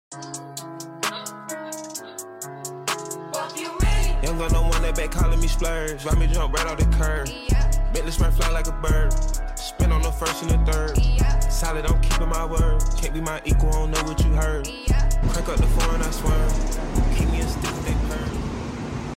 Dyno Tune GTI This is sound effects free download